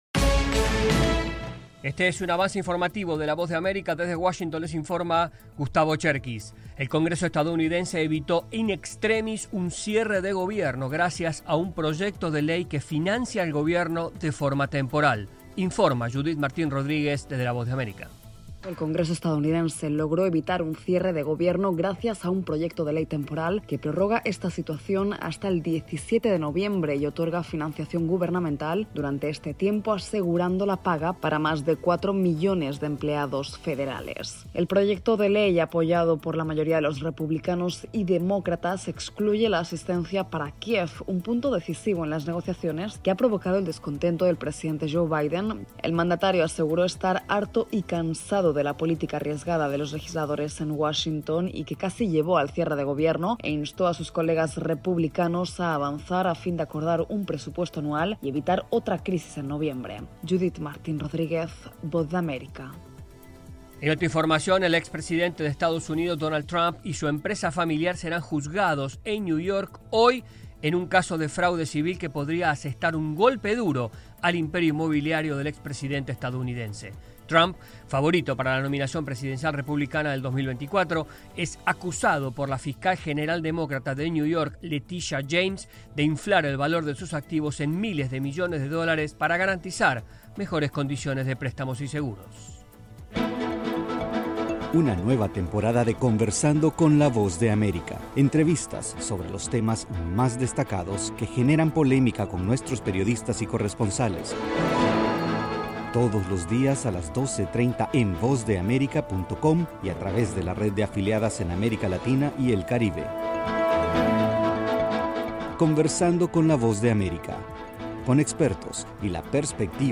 Este es un avance informativo de la Voz de América.